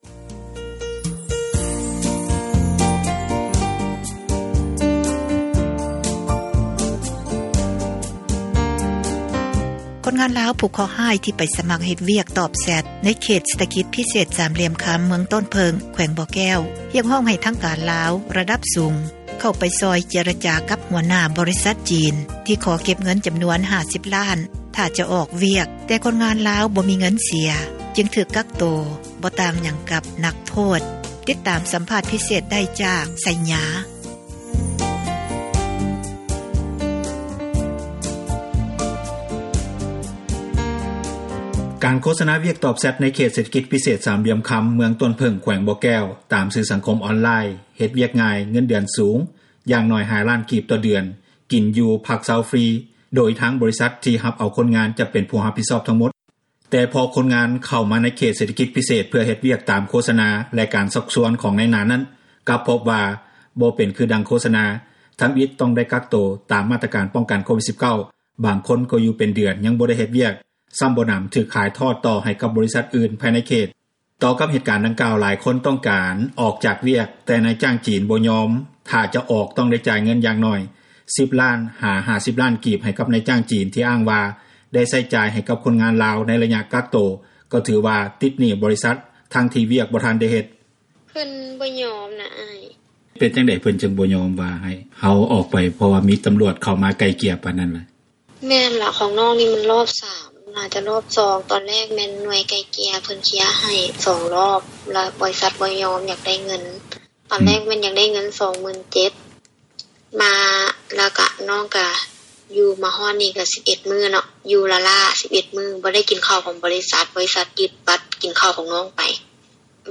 ຟັງການສັມພາດ ຄົນງານຍິງ ທີ່ຂໍການຊ່ອຍເຫລືອ ອອກຈາກວຽກ ຕອບແຊັດ ໃນເຂດເສຖກິຈ ພິເສດສາມຫລ່ຽມຄຳ...